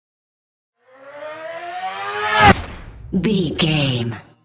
Whoosh fast engine speed
Sound Effects
intense
whoosh